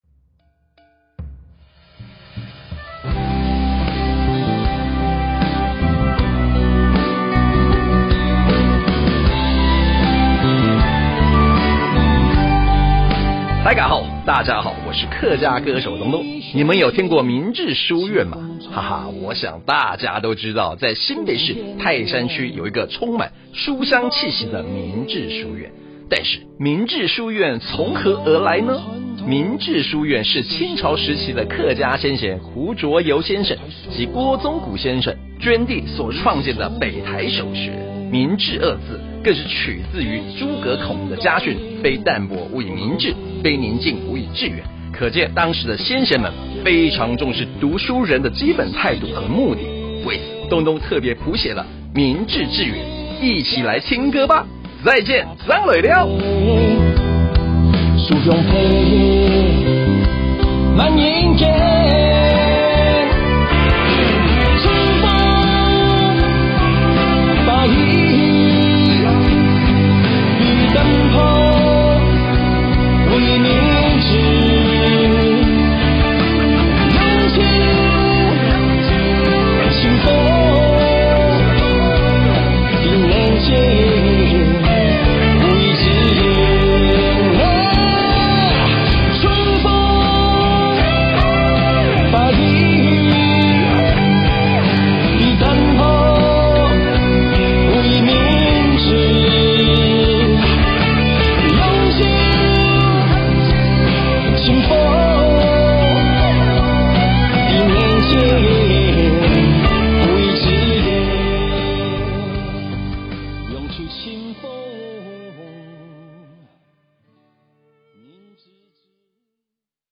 新北市泰山《海陸腔》 精簡版 | 新北市客家文化典藏資料庫